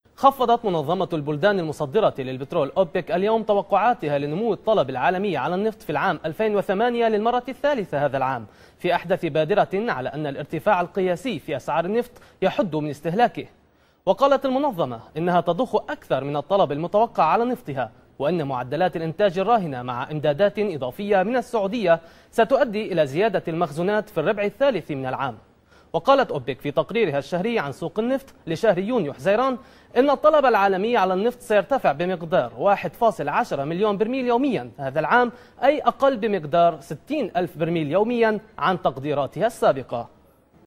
Klasik Arapça Seslendirme
Erkek Ses